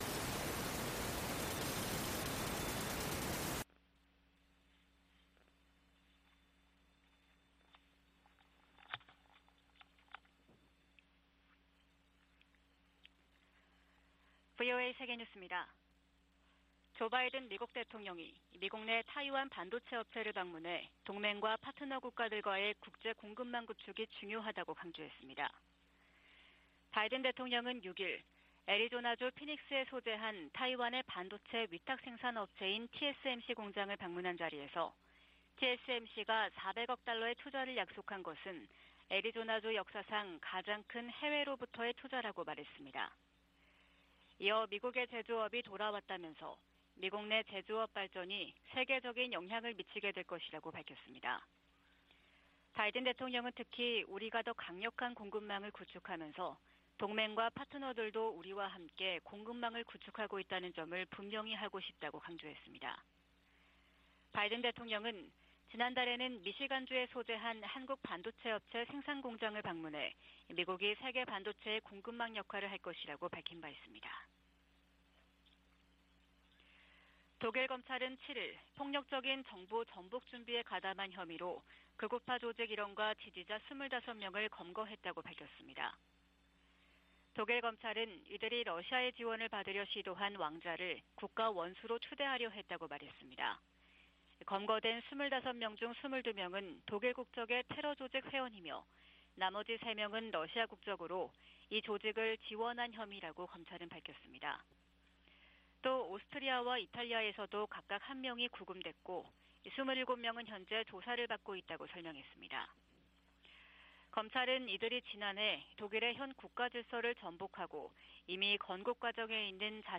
VOA 한국어 '출발 뉴스 쇼', 2022년 12월 8일 방송입니다. 국무부가 중국과 러시아에 유엔 안보리 대북 결의에 따른 의무를 이행하라고 거듭 촉구했습니다. 미국과 호주가 북한의 불법적인 핵과 탄도미사일 프로그램을 해결하겠다는 의지를 거듭 확인하고 국제사회에 유엔 안보리 결의 준수를 촉구했습니다.